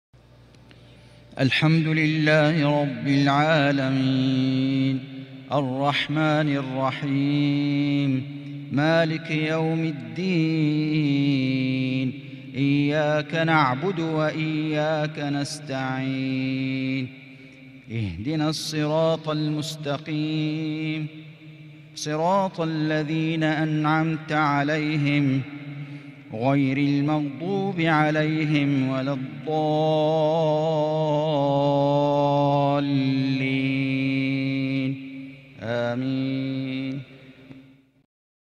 من الحرم المكي